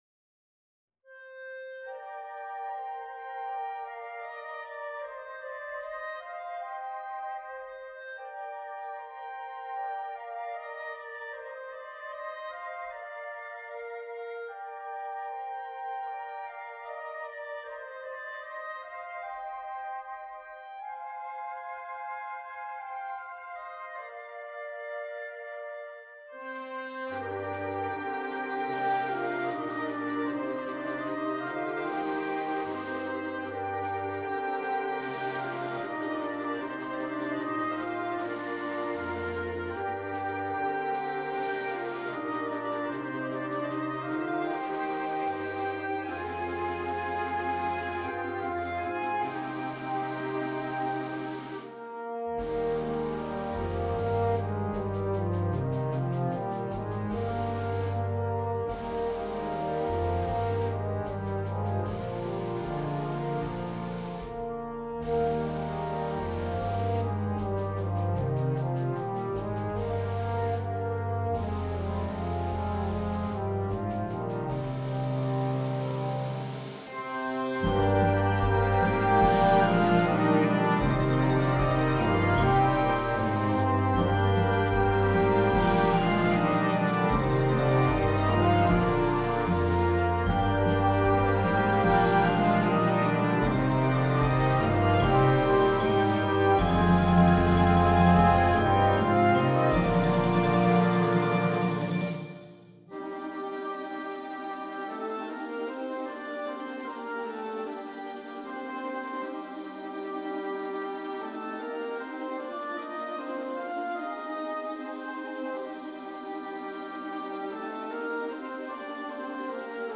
full concert band
A gentle piece for the most part.